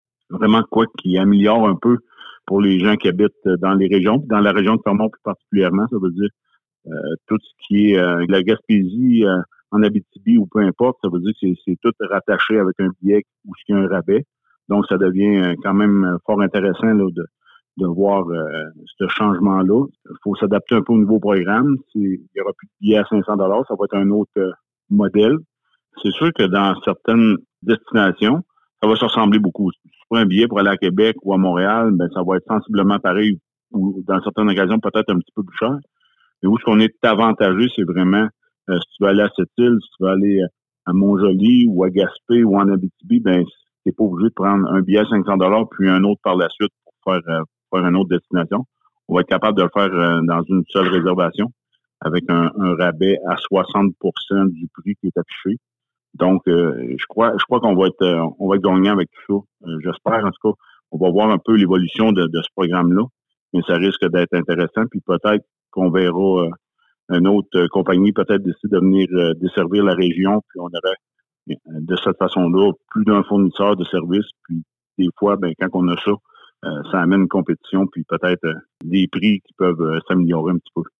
Écoutez l’entrevue complète réalisée avec Martin St-Laurent, maire de Fermont :